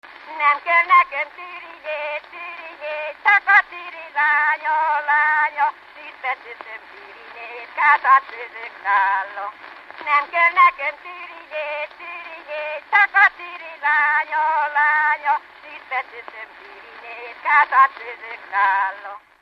Alföld - Csongrád vm. - Tápé
Stílus: 6. Duda-kanász mulattató stílus